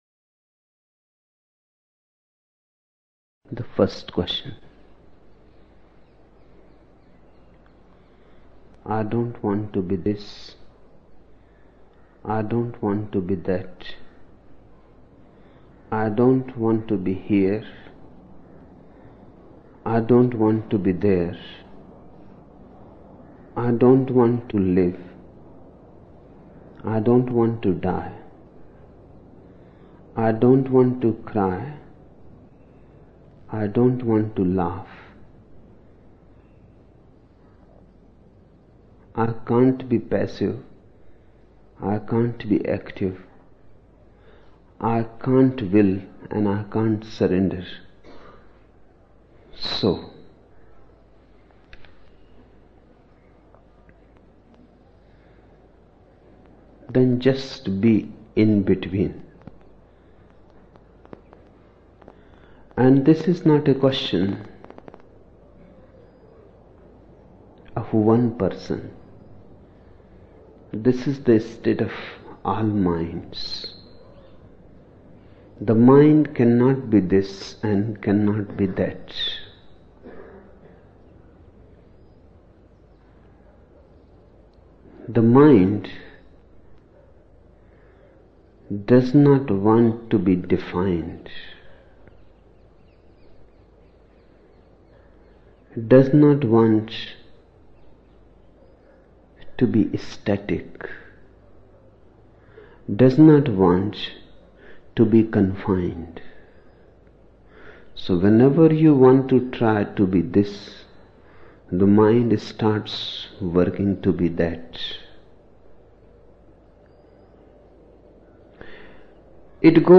26 October 1975 morning in Buddha Hall, Poona, India